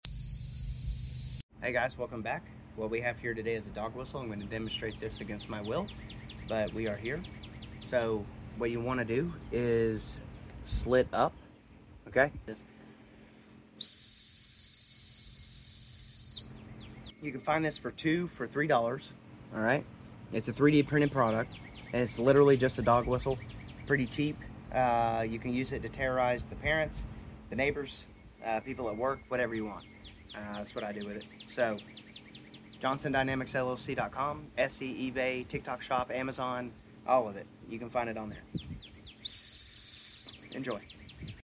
High Pitched Dog Whistle on sound effects free download